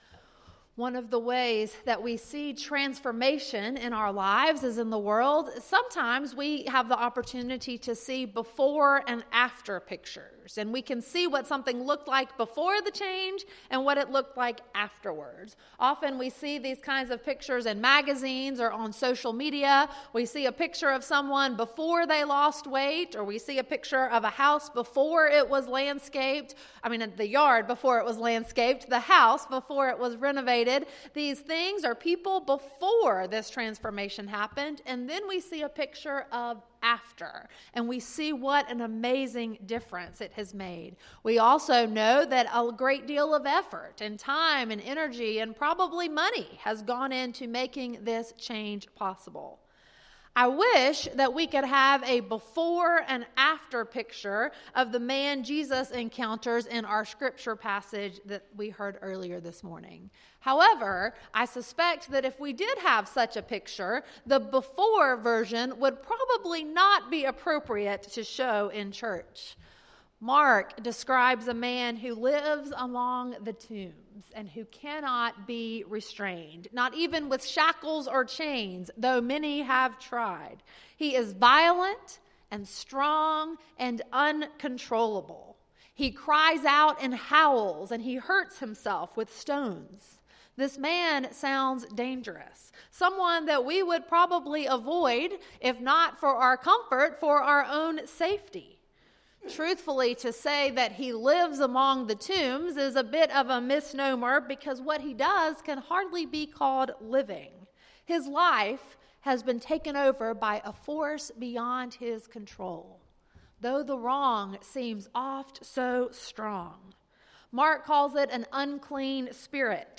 Sermon, Worship Guide, and Announcements for January 26, 2020 - First Baptist Church of Pendleton